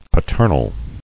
[pa·ter·nal]